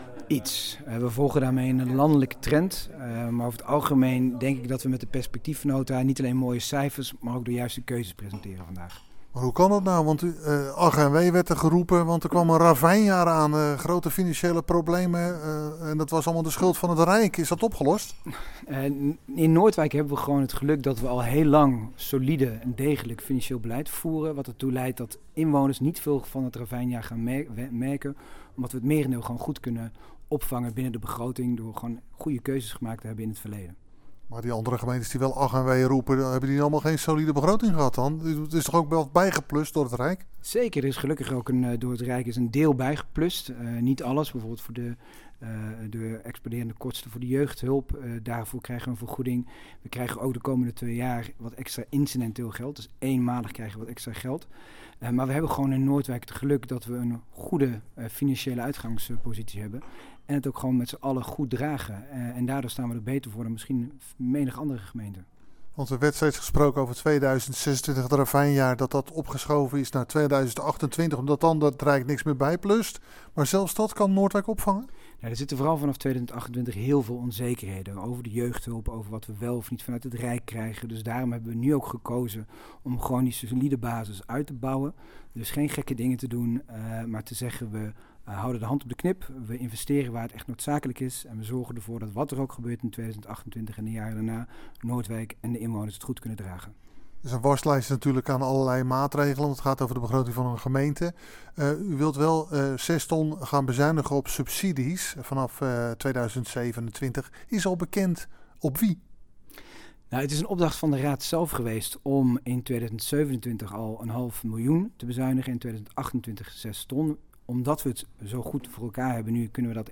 Hieronder het radio-interview met wethouder Pim van Strien over de perspectiefnota: